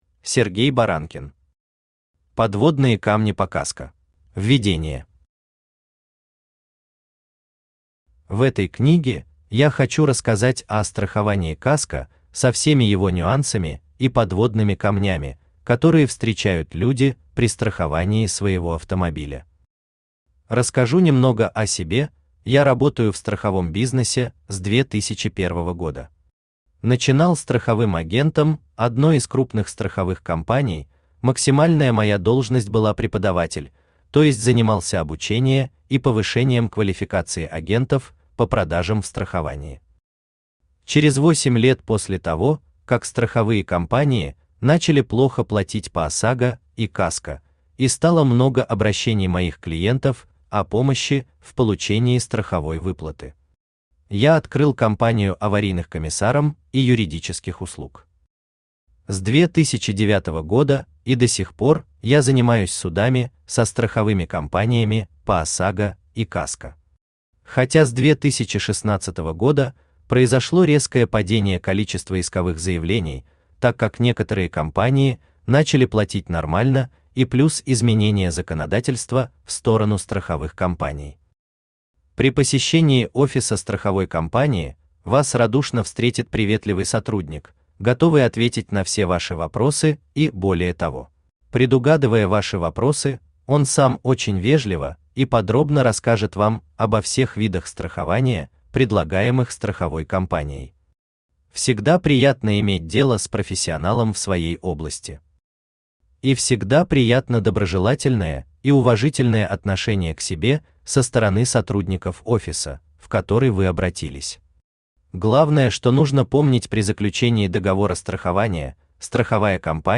Аудиокнига Подводные камни по КАСКО | Библиотека аудиокниг
Читает аудиокнигу Авточтец ЛитРес.